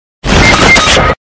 Fortnite Death Earrape